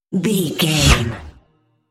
Pass by sci fi fast
Sound Effects
Fast
futuristic
pass by
vehicle